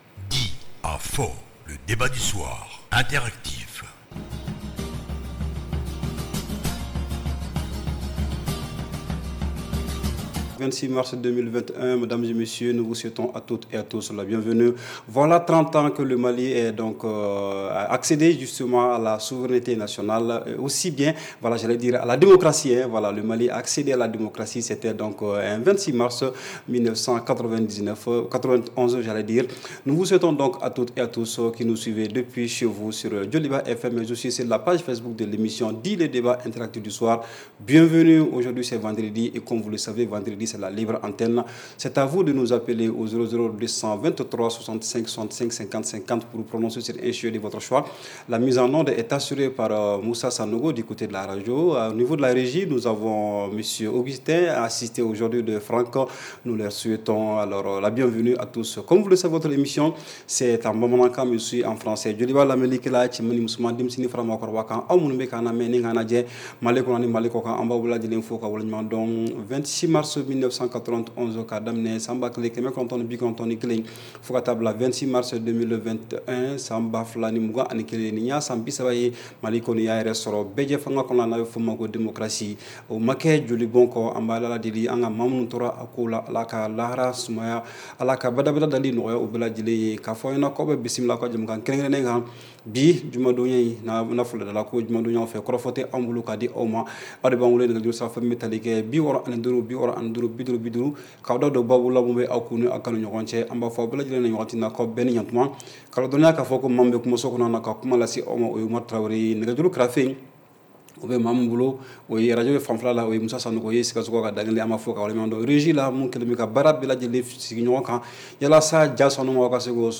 REPLAY 26/03 – « DIS ! » Le Débat Interactif du Soir
Appelle-nous et donne ton point de vue sur une question d’actualité (politique, économique, culturelle, religieuse, etc.). Pas de sujets tabous : arguments, contre arguments !